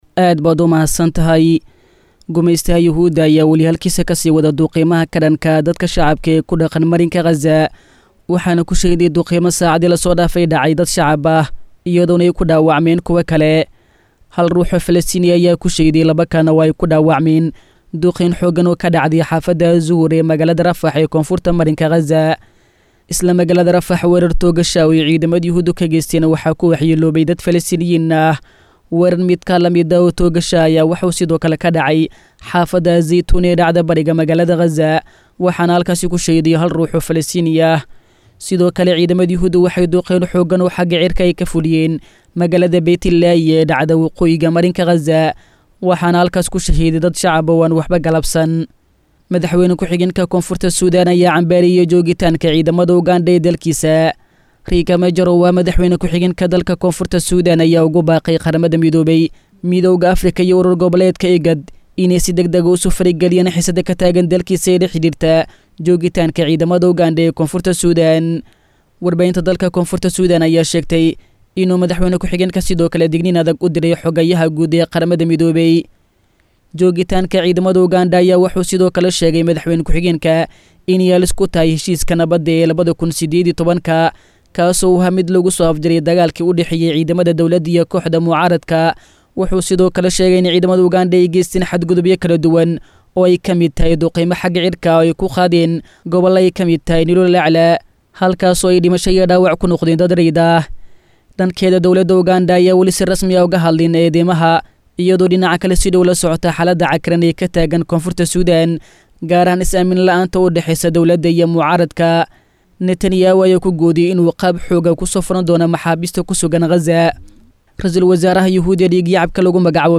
Xubinta Wararka Caalamka oo ku baxda Barnaamijka Dhuuxa Wareysiyada ee idaacadda Islaamiga ah ee Al-Furqaan, waxaa lagu soo gudbiyaa wararkii ugu dambeeyay ee daafaha Caalamka.